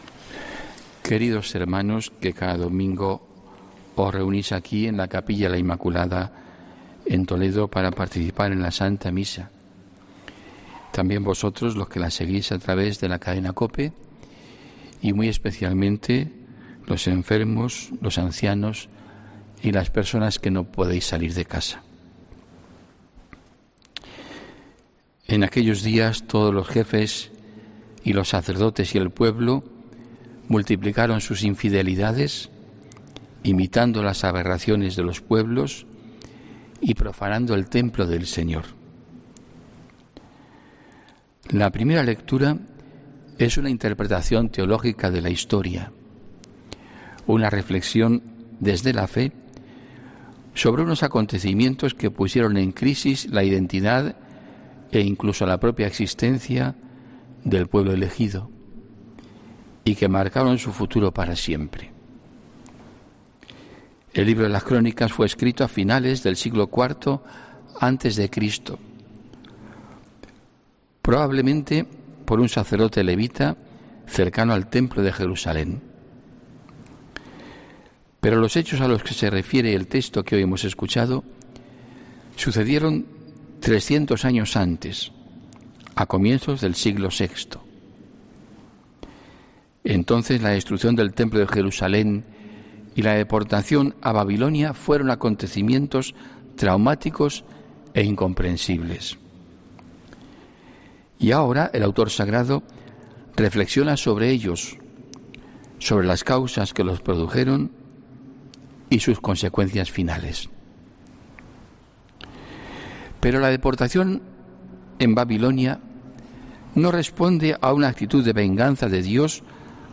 HOMILÍA 14 MARZO 2021